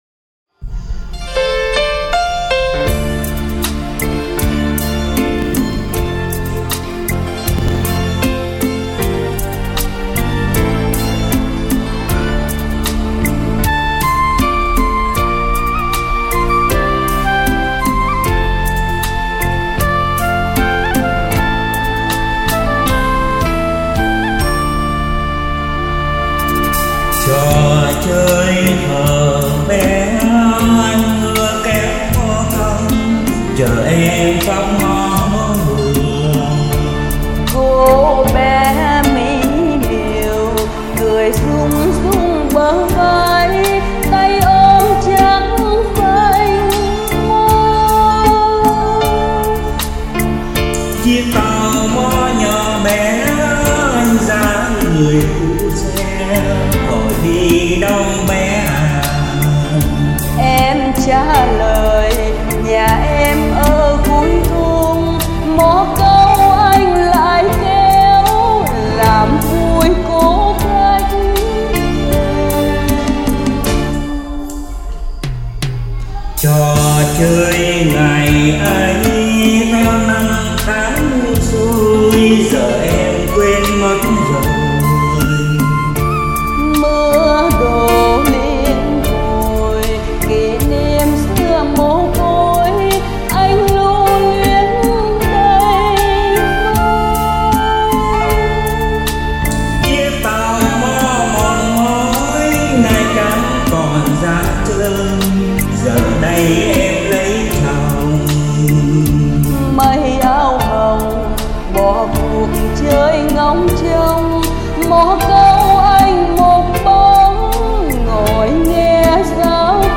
song ca